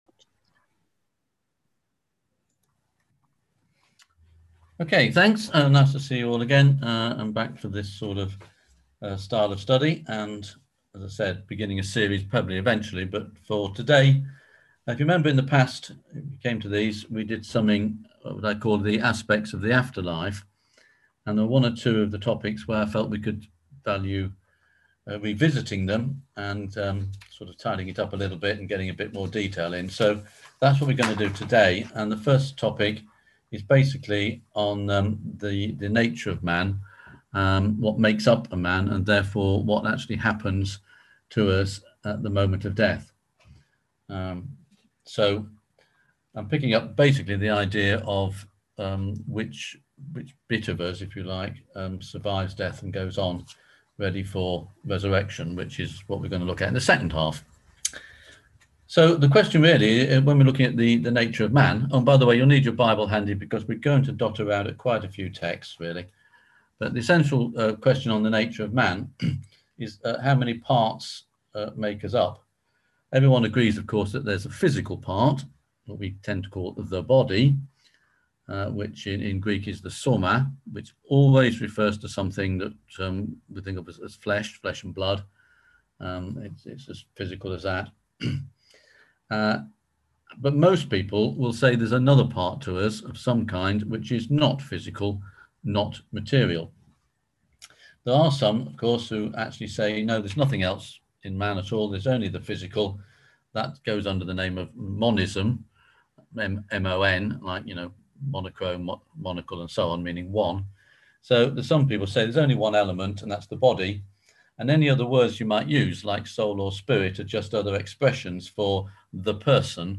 On September 9th at 7pm – 8:30pm on ZOOM